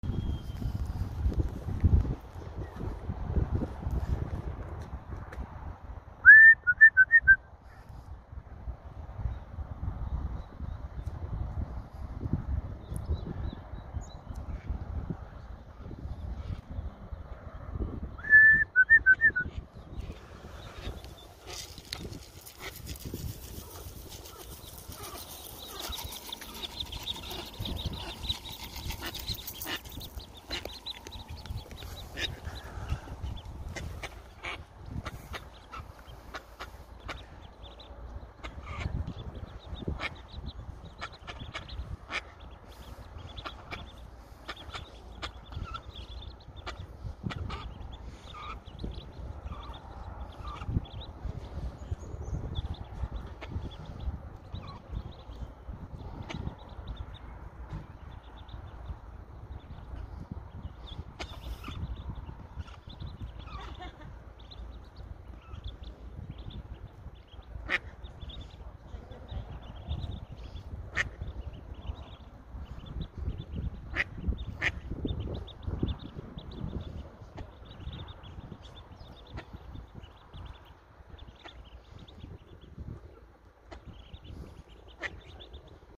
Raising wild ducks to come sound effects free download
Raising wild ducks to come to me when I whistle!